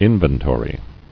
[in·ven·to·ry]